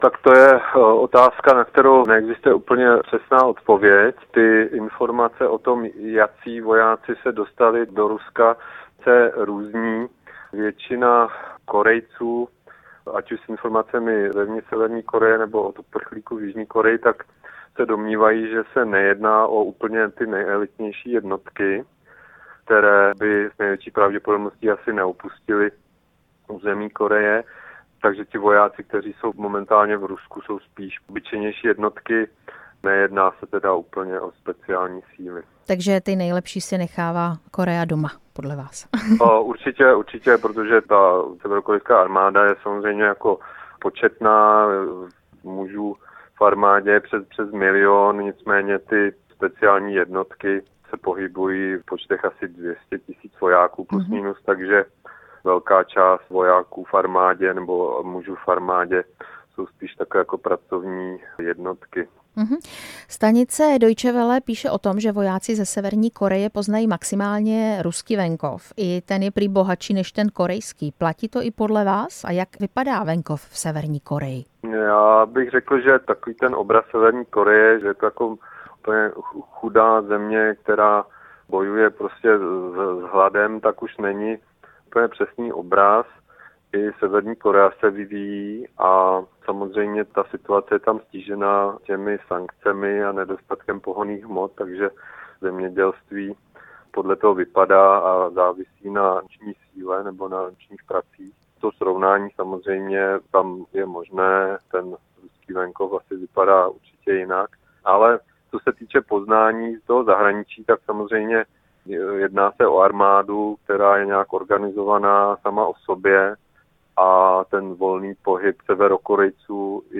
Ve vysílání Radia Prostor jsme téma probírali s koreanistou